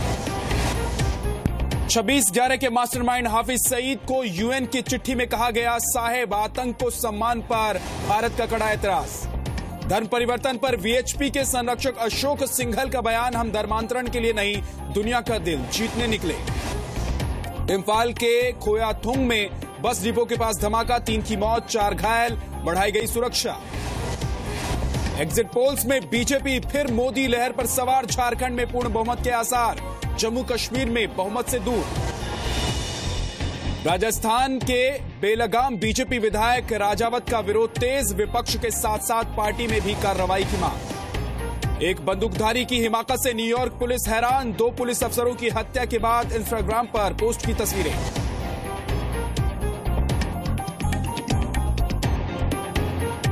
Listen to top headlines of the day